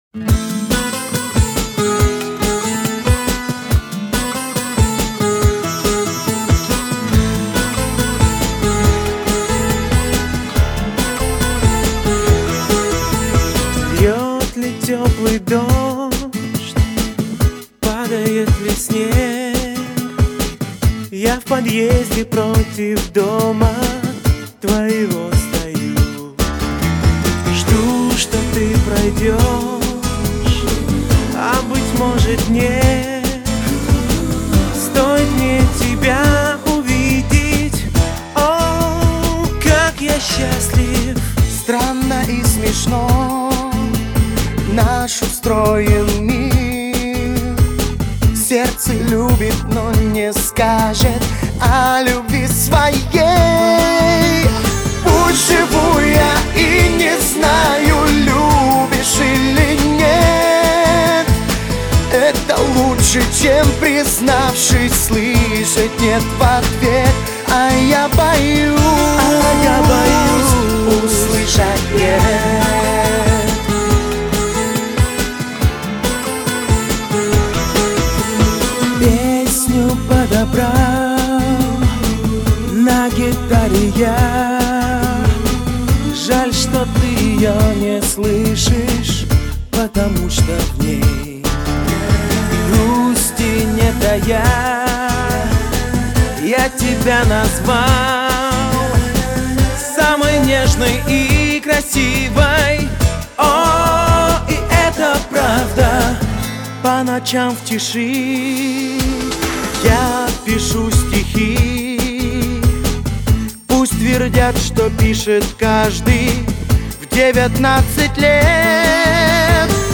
это яркий пример поп-рока с элементами этники